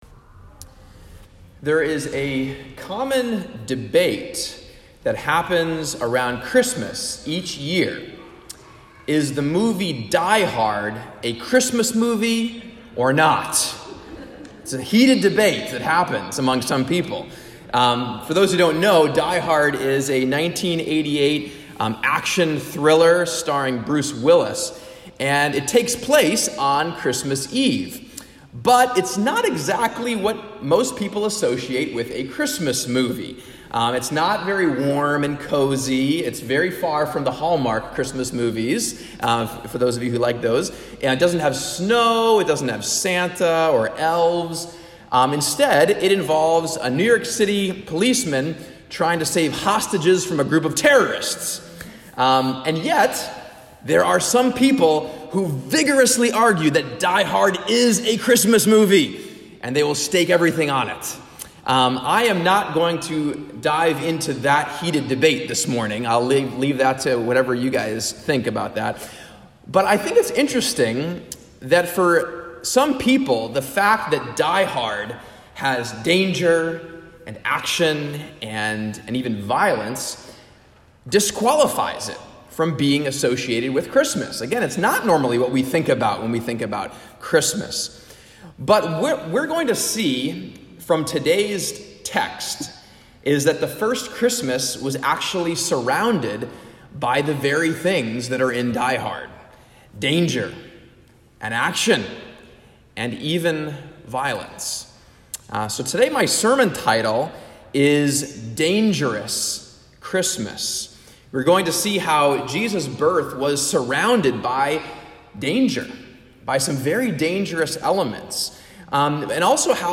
"Dangerous Christmas" A message on the first Sunday after Christmas, looking at Matthew 2:13-23, where Mary, Joseph, and Jesus have to flee to Egypt because of danger they faced from King Herod. We reflect on the danger in our world, how God responds to this danger, and how we can respond as well.